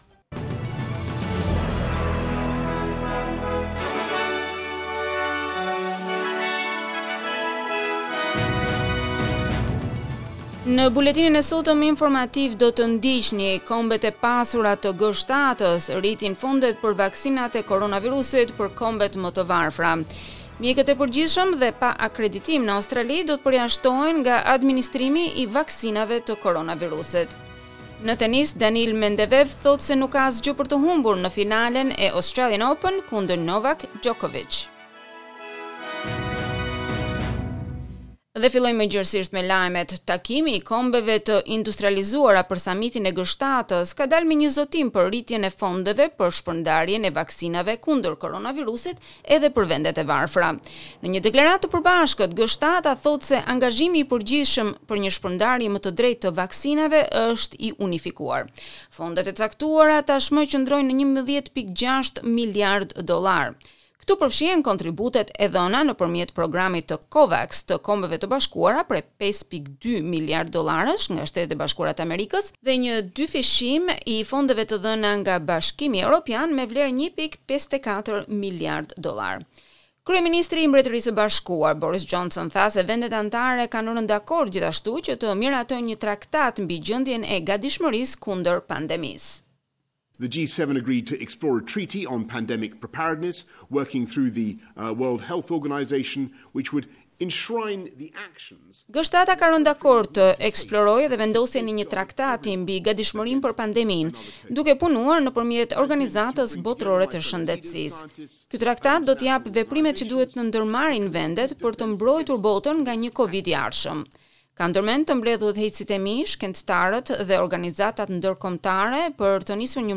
SBS News Bulletin in Albanian - 20 February 2021